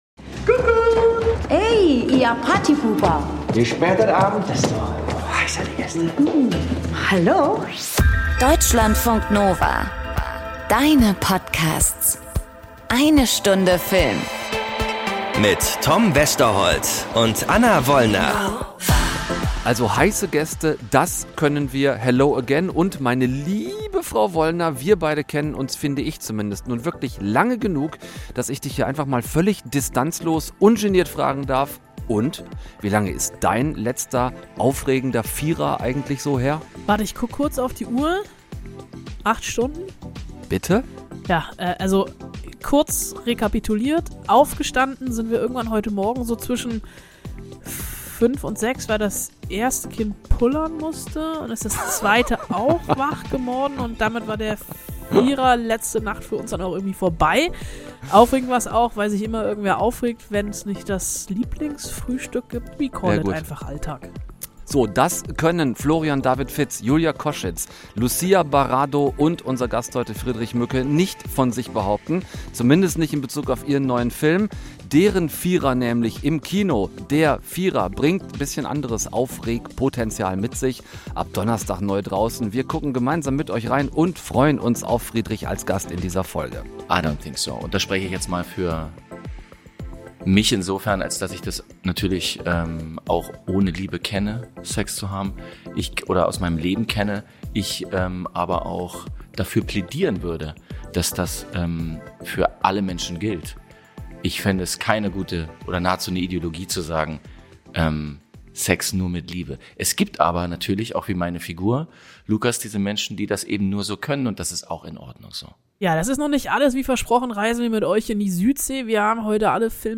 1 "Der Vierer" - Wenn Erotik auf Komödie trifft 54:54 Play Pause 3h ago 54:54 Play Pause Na później Na później Listy Polub Polubione 54:54 Die Erotik-Komödie ist der vielleicht schwierigste Genre-Mix, den es überhaupt gibt. Schauspieler Friedrich Mücke hat ihn dennoch versucht und ist in dieser Folge zu Gast.